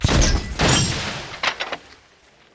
bombard.mp3